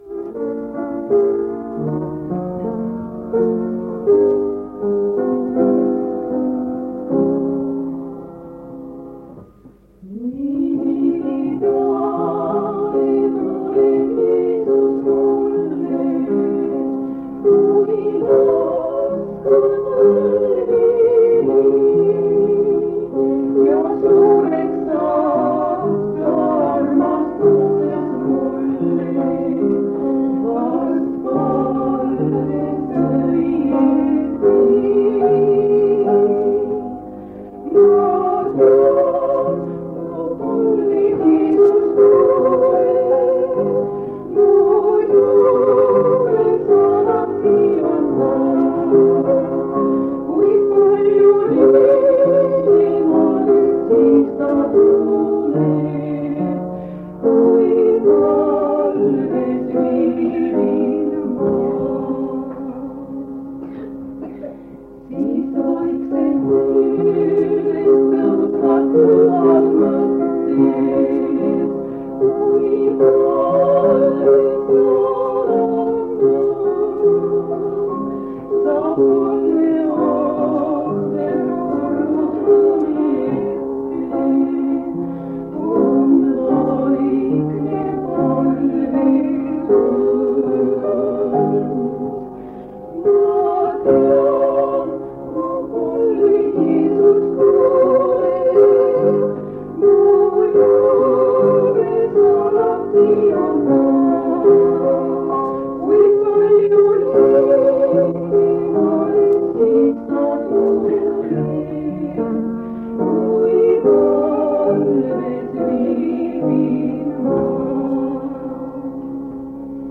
kategooria Audio / Koosolekute helisalvestused
On 1977 aasta kevad. Paide adventkirikus toimub
Täpsemaid kuupäevi pole teada ning jagasin lintmaki lintidele talletatu kuueks päevaks.